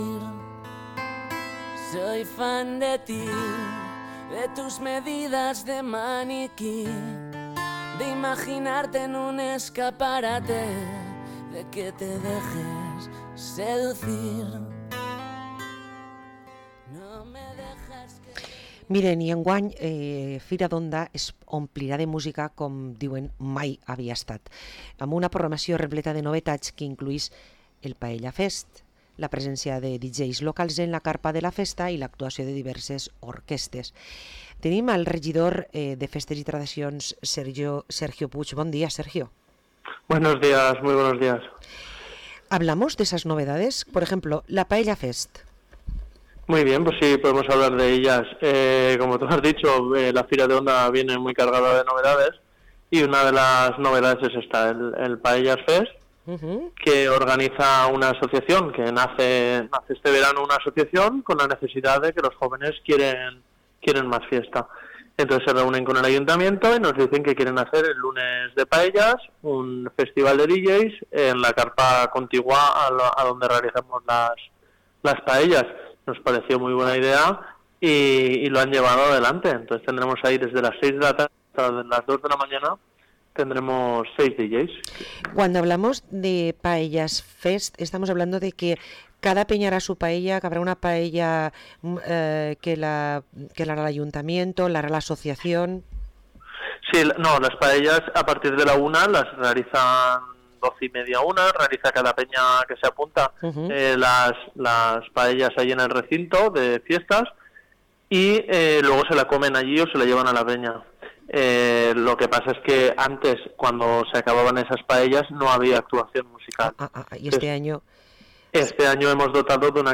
Parlem amb Sergio Puig, regidor de Festes i Tradiciones a la localitat d´Onda